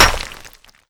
BulletImpact_Concrete01.wav